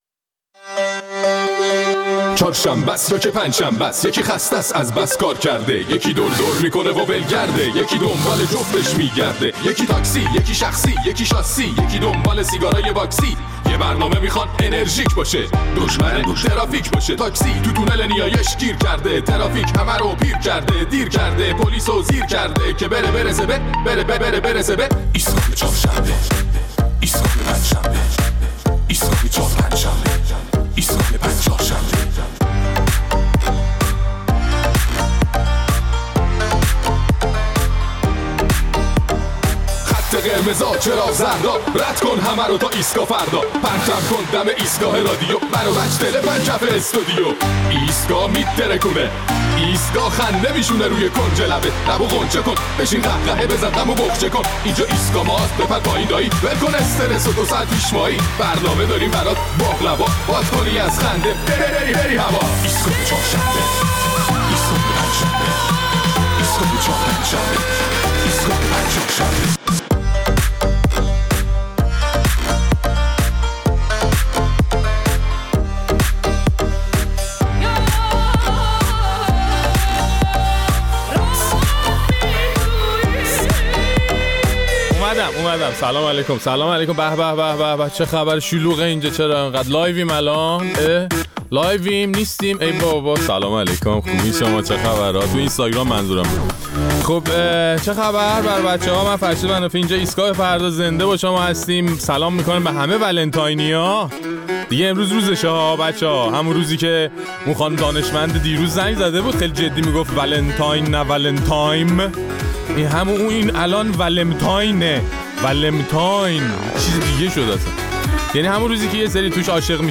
در این ایستگاه فردا ادامه نظرات مخاطبانمان را در مورد کم و کیف روز ولنتاین می‌شنویم.